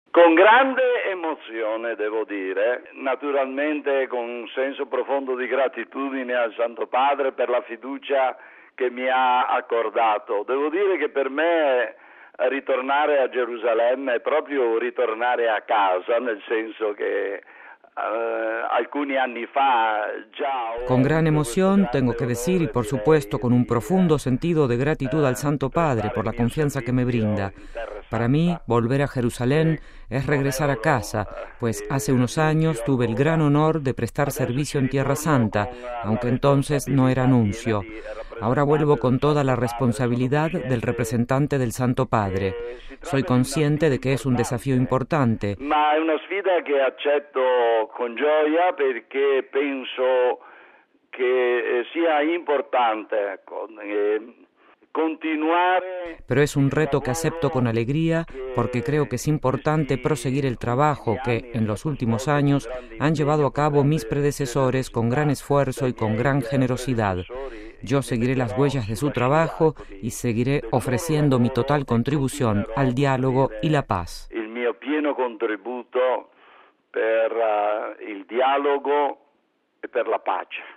Mientras, el nuevo Nuncio en Israel y Delegado Apostólico en Jerusalén y Palestina, el Arzobispo Giuseppe Lazzarotto – nombrado hace unos días por Benedicto XVI – reiteró, ante los micrófonos de Radio Vaticano, la importancia de que prevalezca el diálogo para lograr la paz.